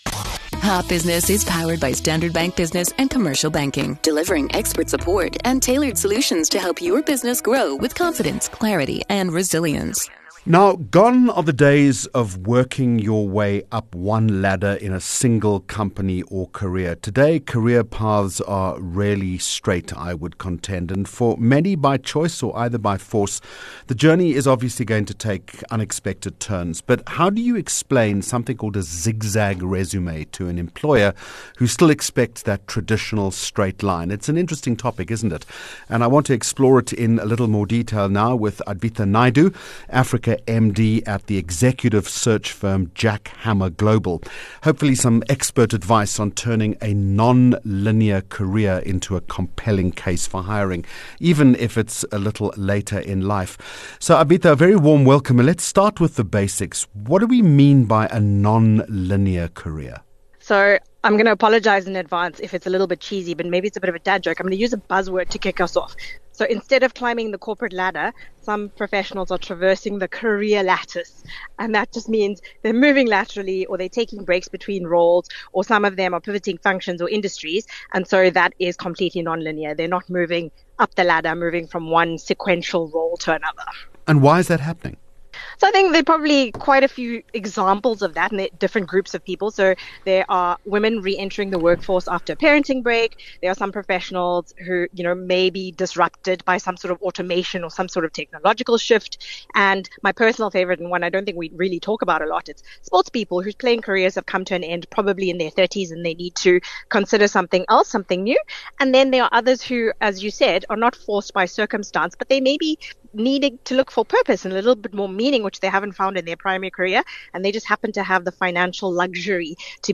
19 Jun Hot Business Interview